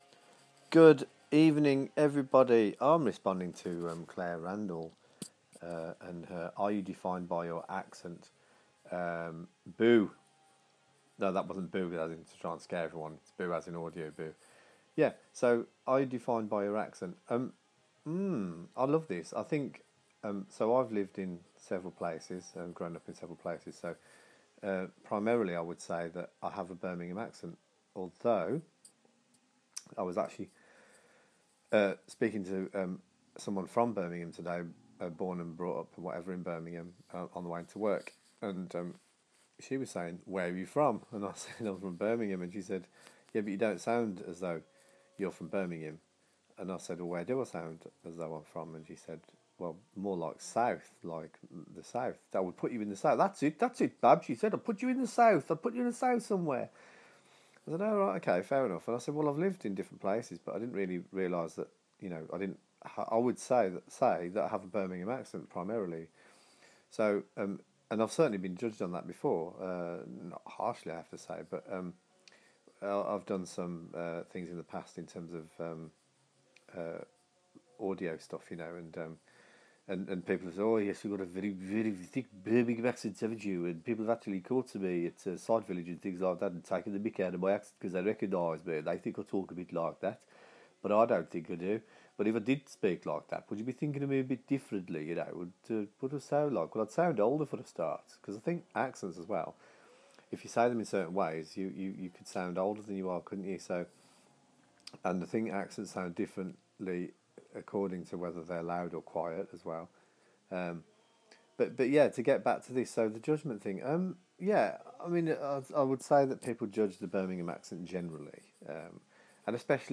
All my accent incarnations represented here...